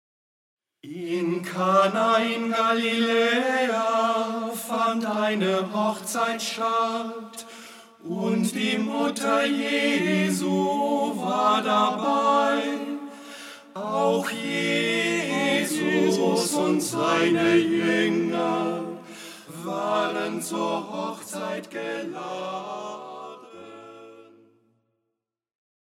kirchlicher Gesänge und Gebete